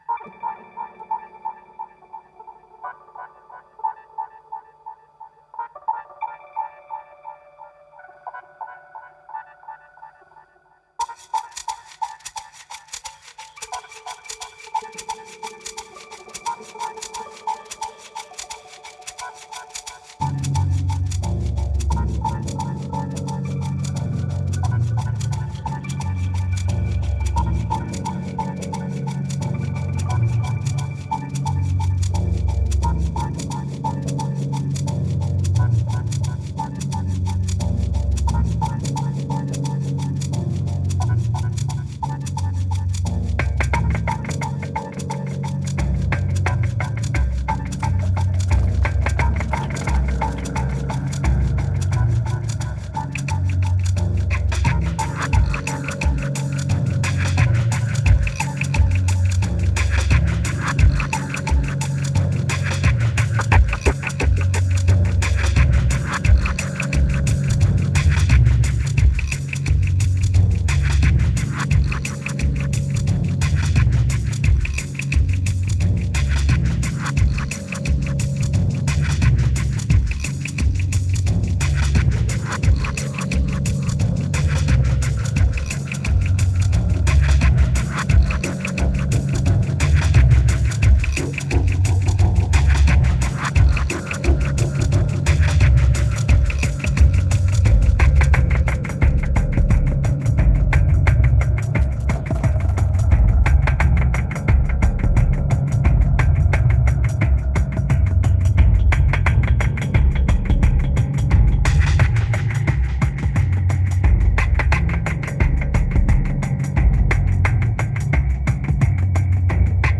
2181📈 - 44%🤔 - 88BPM🔊 - 2011-10-22📅 - 2🌟